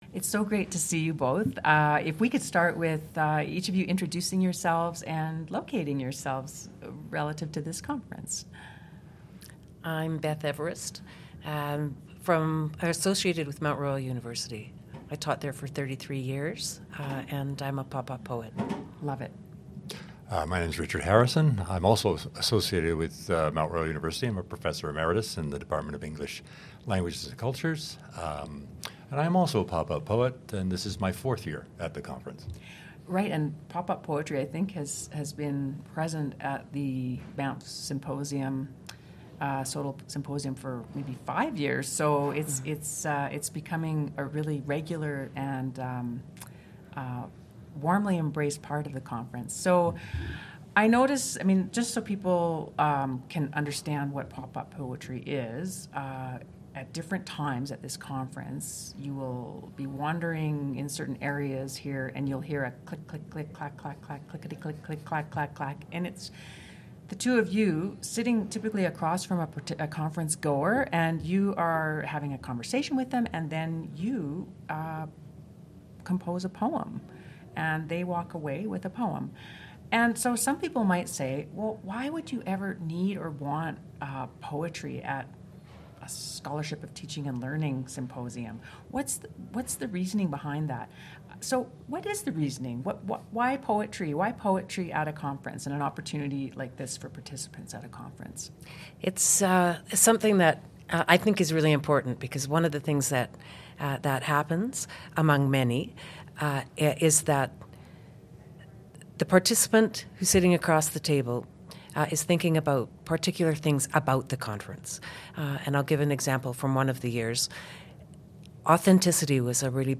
The SOTL in 60+ podcast series consisted of ten episodes recorded during the 2024 Symposium for Scholarship of Teaching and Learning November 7-9, 2024 in Banff, Alberta, Canada.